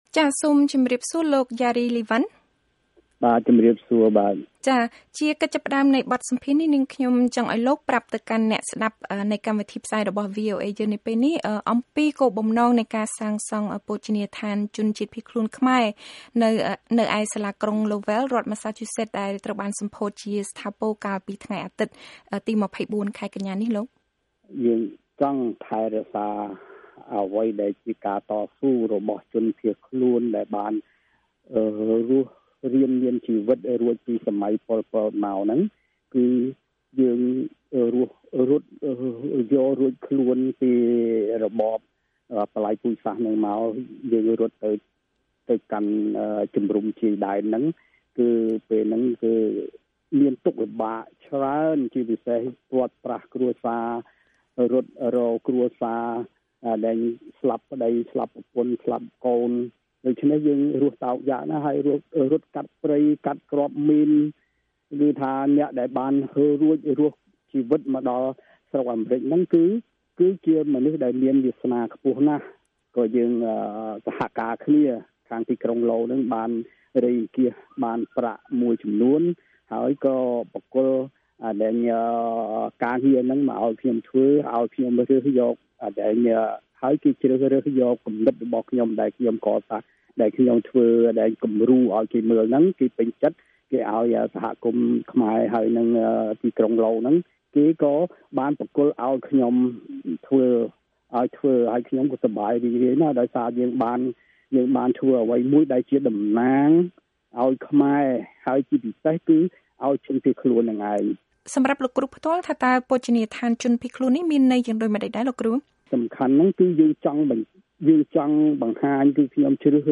បទសម្ភាសន៍ VOA៖ បូជនីយដ្ឋានជនភៀសខ្លួនខ្មែរនៅក្រុងឡូវែលរំឭកប្រវត្តិសាស្ត្រនៃការតស៊ូរបស់ខ្មែរនៅអាមេរិក